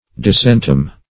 Disentomb \Dis`en*tomb"\
disentomb.mp3